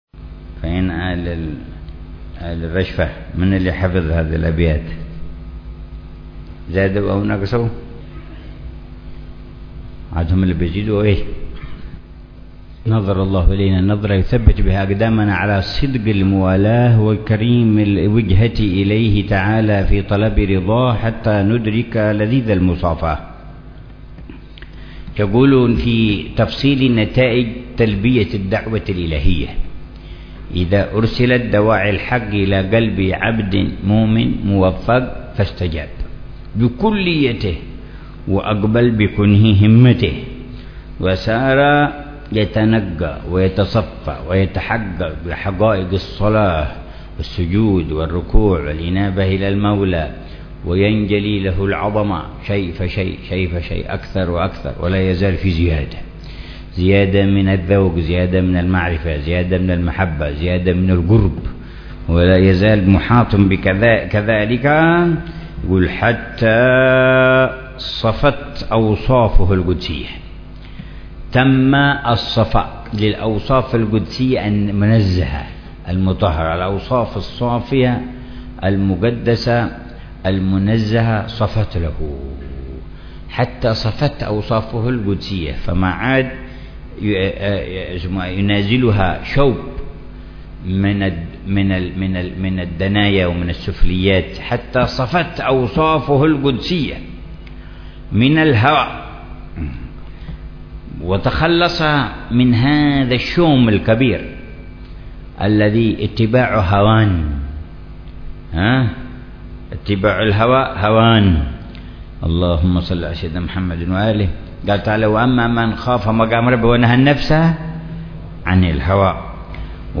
رشفات أهل الكمال ونسمات أهل الوصال - الدرس السابع والأربعون
شرح الحبيب عمر بن محمد بن حفيظ لرشفات أهل الكمال ونسمات أهل الوصال.